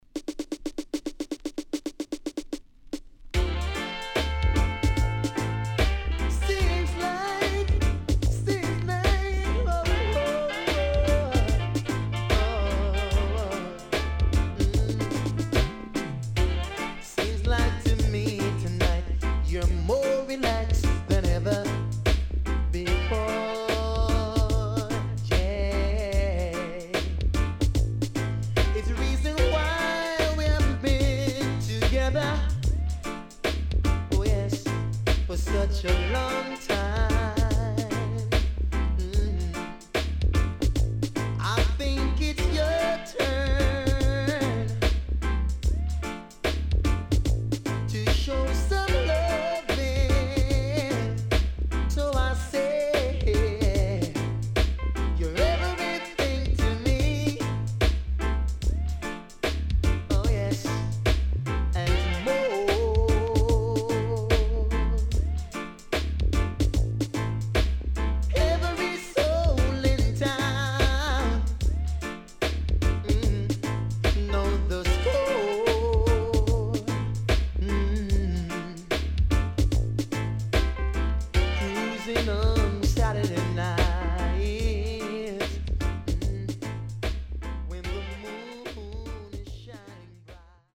HOME > LP [DANCEHALL]
SIDE B:少しチリノイズ入りますが良好です。